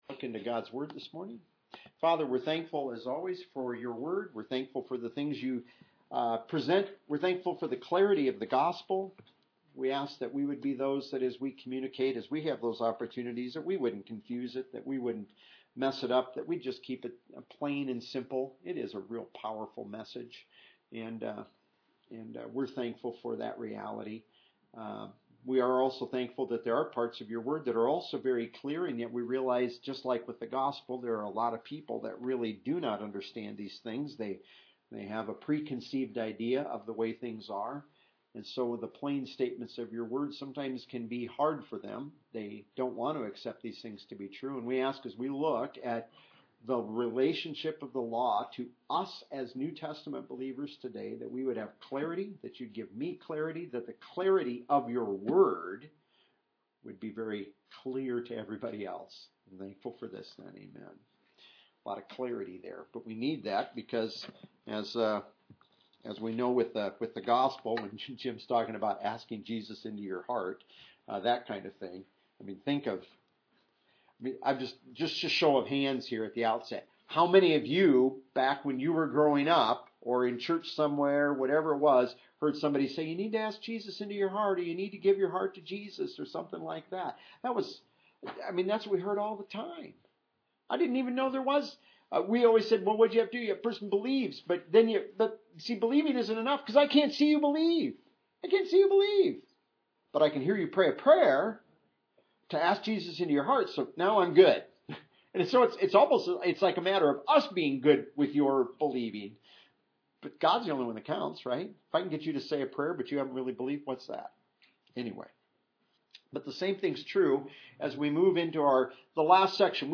PLEASE NOTE: When you are listening to these audio files, keep in mind that you might hear the sound of children or of people asking questions during the teaching.
You will not find a strict, formal, religious ceremony atmosphere in these Bible teachings. Also note that due to technical difficulties, some earlier recordings might be more difficult to hear.